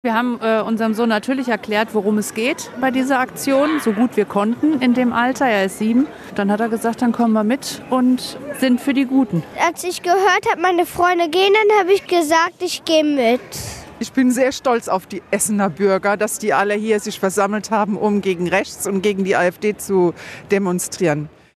demo-gegen-afd-eltern.mp3